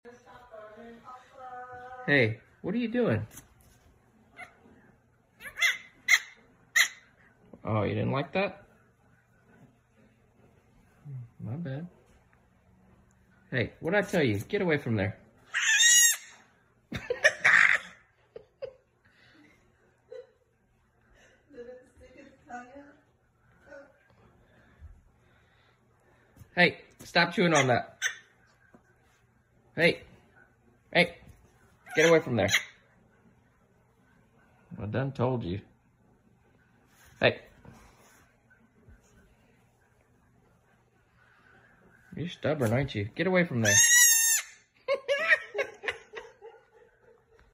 Get your self a baby goat and laugh!